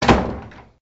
Door_Close_1.ogg